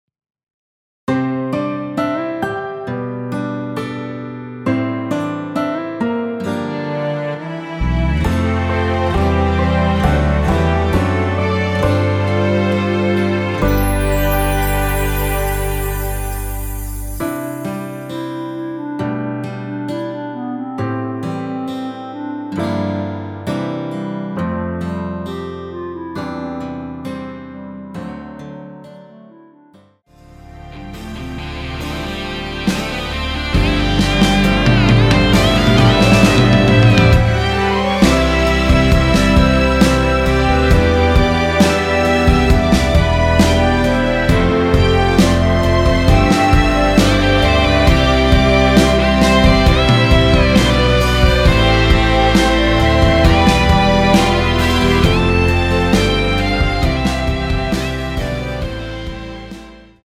멜로디 MR입니다.
원키에서(-3)내린 멜로디 포함된 MR입니다.
앞부분30초, 뒷부분30초씩 편집해서 올려 드리고 있습니다.